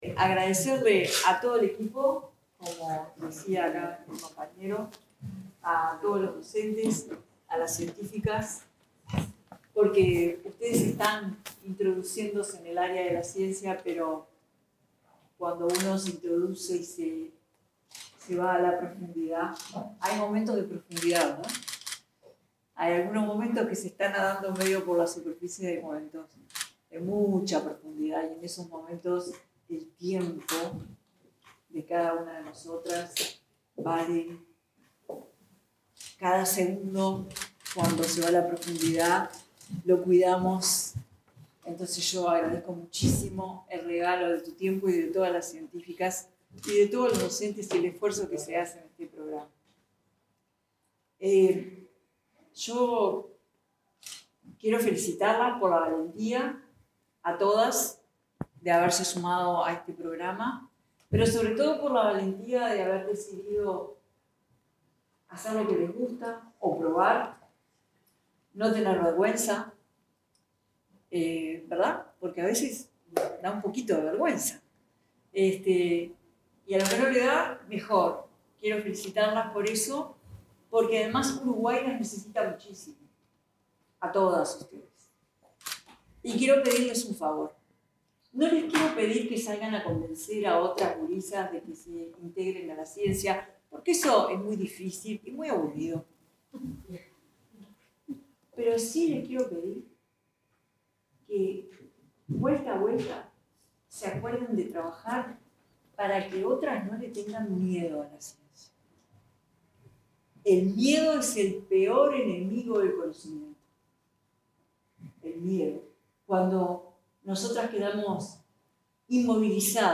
Palabras de la presidenta en ejercicio, Carolina Cosse
Palabras de la presidenta en ejercicio, Carolina Cosse 26/09/2025 Compartir Facebook X Copiar enlace WhatsApp LinkedIn La presidenta de la República en ejercicio, Carolina Cosse, se expresó sobre la importancia del proyecto Más Mujer en Ciencia, en el acto de cierre del programa, en el Museo de Historia Natural Carlos Torres de la Llosa.